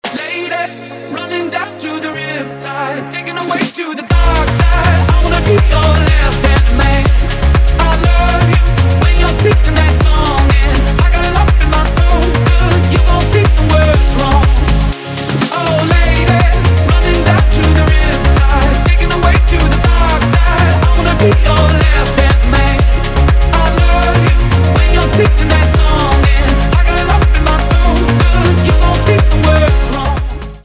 танцевальные
битовые , басы , качающие